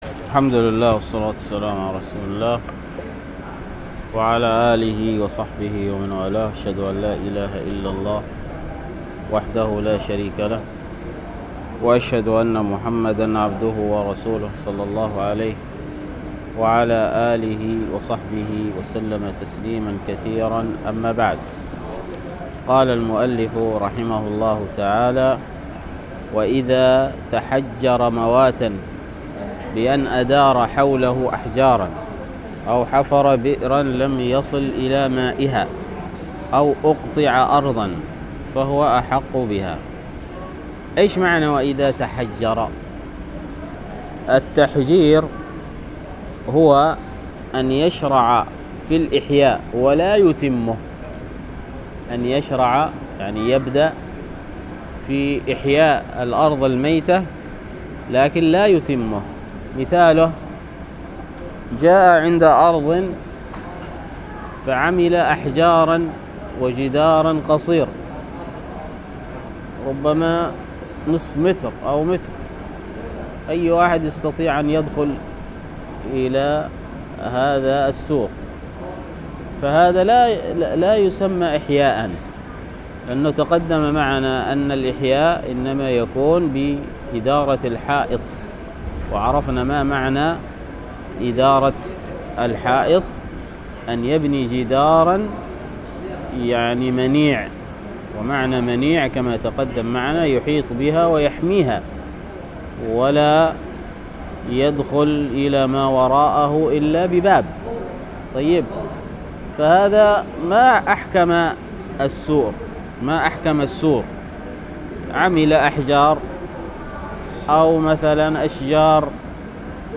الدرس في كتاب البيوع من فتح المعين في تقريب منهج السالكين 34